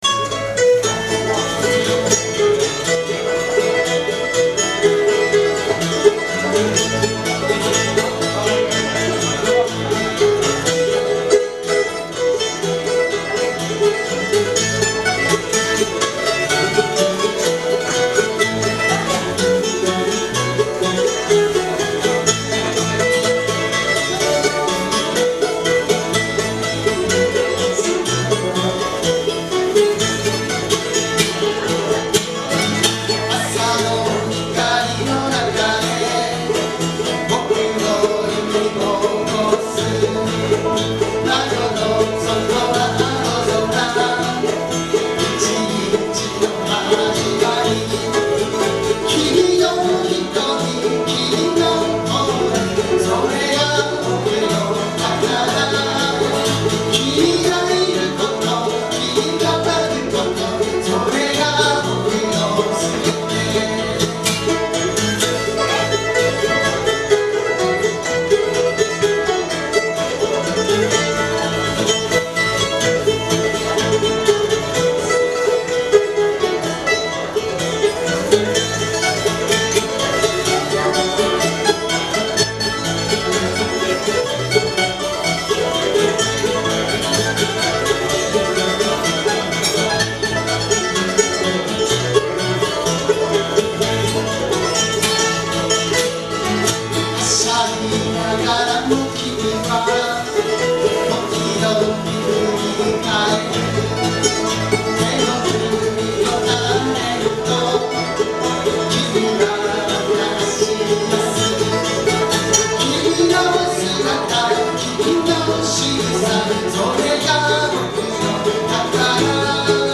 Bluegrass style Folk group
Key of A
マンドリンがもっとも得意とするAのキーで演奏しています。
録音場所: 風に吹かれて(大森)
マンドリン
バンジョー
ボーカル、ギター
ベース